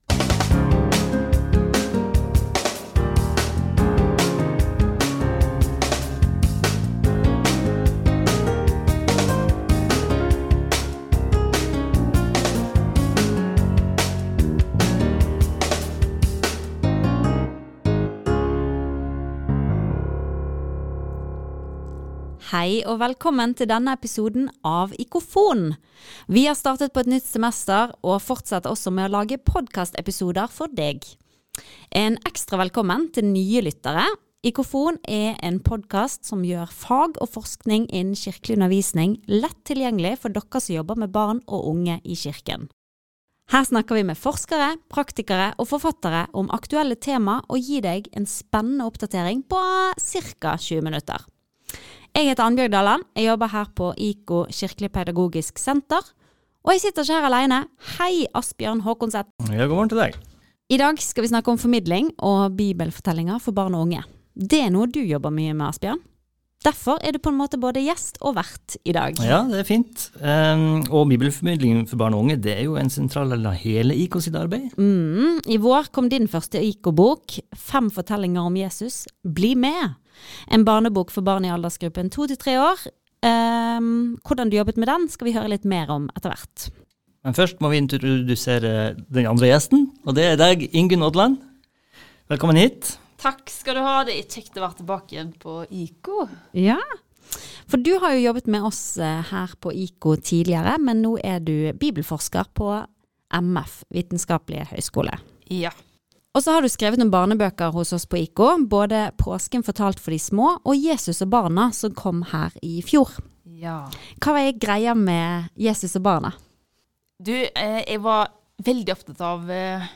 I denne episoden av IKOfon har vi snakket med noen som jobber mye med dette, og tatt utgangspunkt i to barnebøker som er gitt ut på IKO.